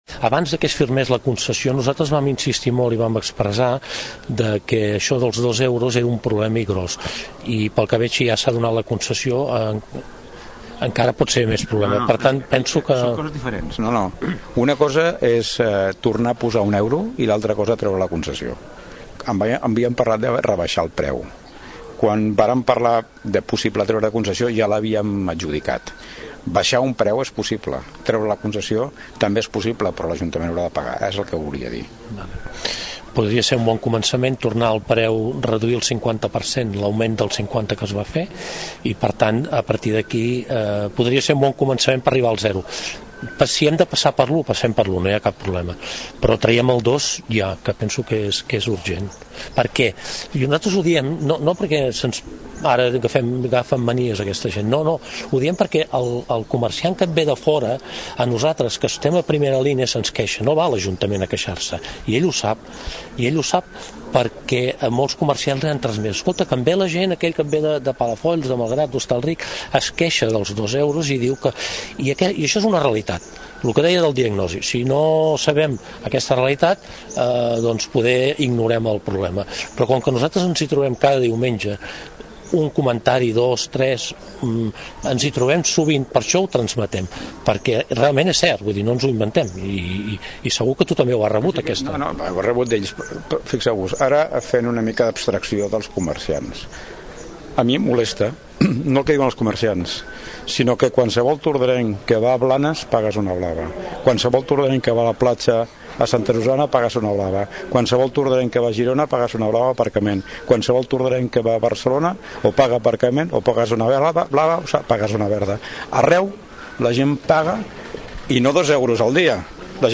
Aquesta era la conversa que mantenien